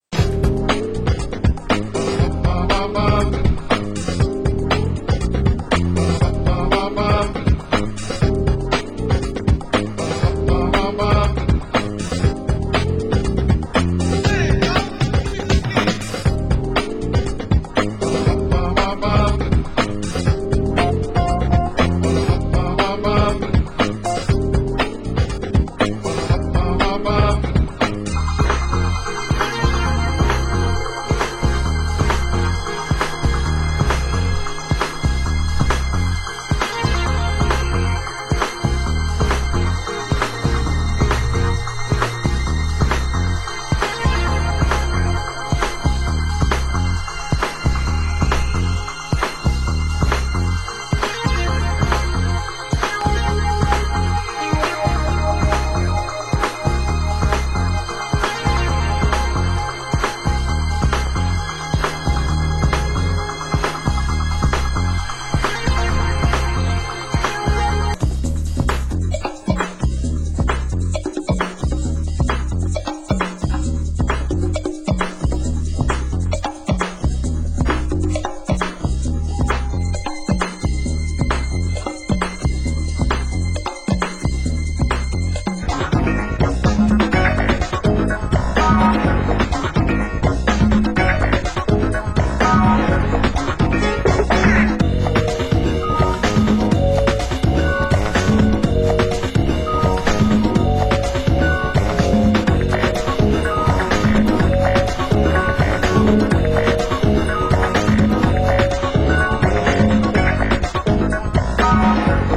Genre: Funky House